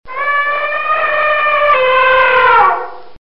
Звук тяжелых шагов слона